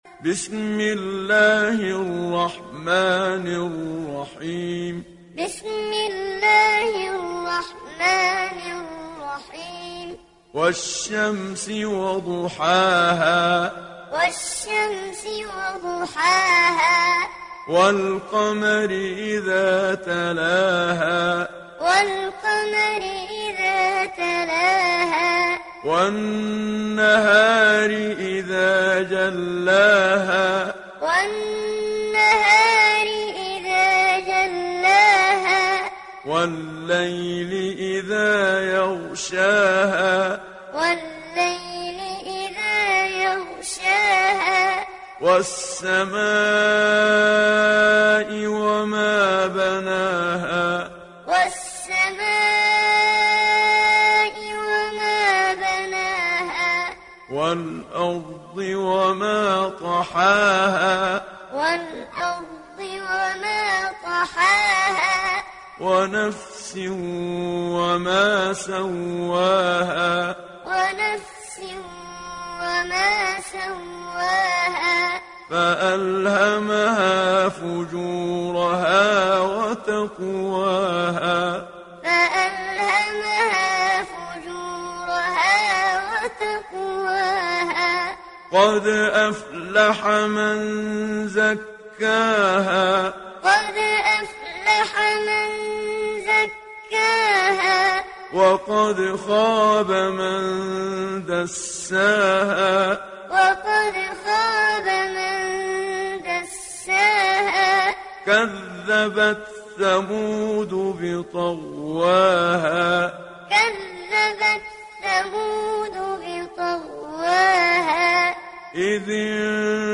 İndir Şems Suresi Muhammad Siddiq Minshawi Muallim